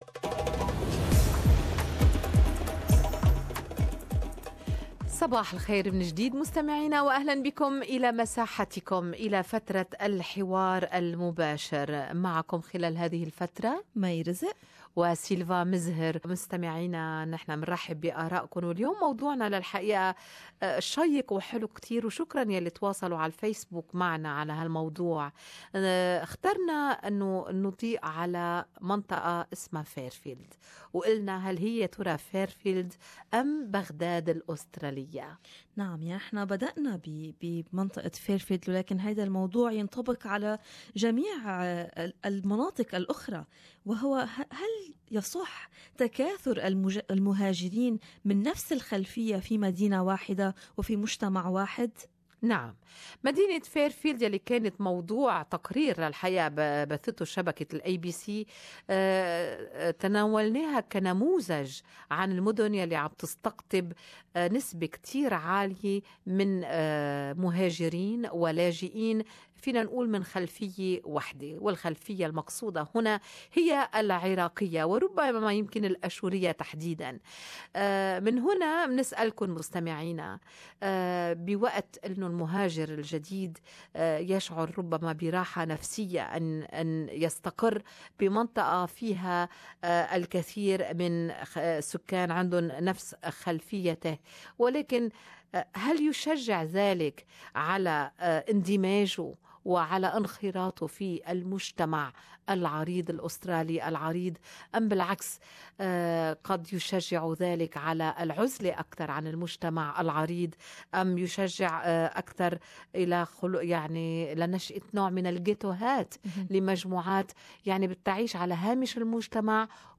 What happens when the majority of an ethnic group settles in a specific area? Does that help refugees to integrate into the Australian society? Listen to listeners commenting on the subject during the Good Morning Australia show.